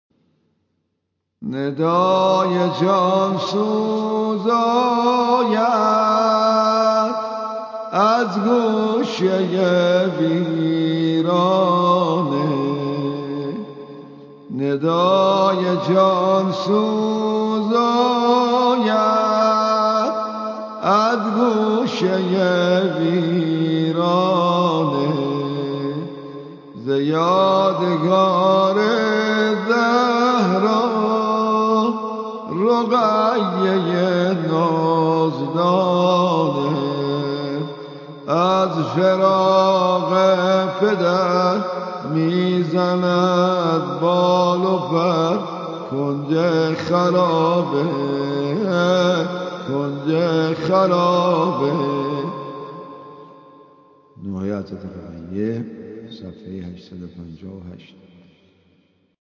roghaye_nohe5.mp3